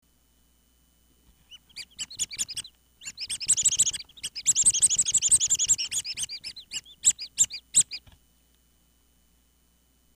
パックを押すとぴよぴよ鳴きます。
フエガムや、ピーと鳴りながら飛んでいく風船の口についている笛と同じような笛を、発泡スチロールと牛乳パックを利用して作り、蛇腹に折った牛乳パックの上部に取り付けました。
piyopiyo-hiyoko.mp3